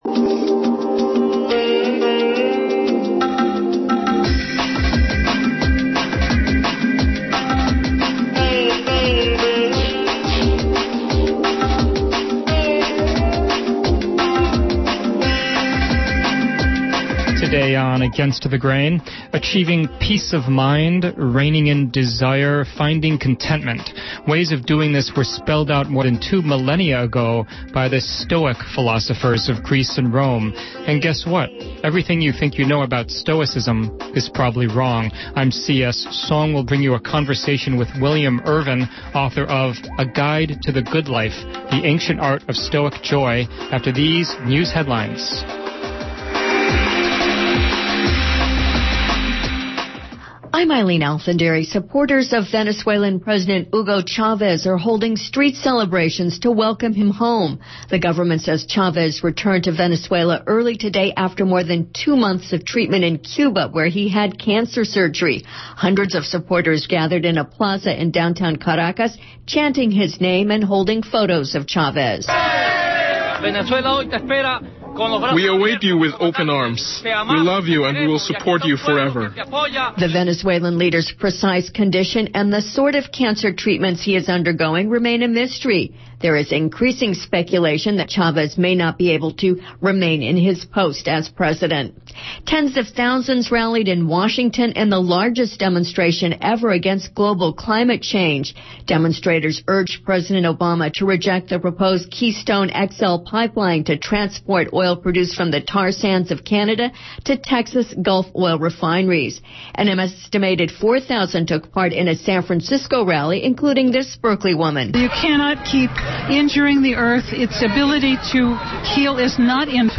Our main piece this week is a radio adaption of the film Flight From Death, which provides a solid introduction into the ideas of Ernest Becker as published in his 1974 book, The Denial of Death.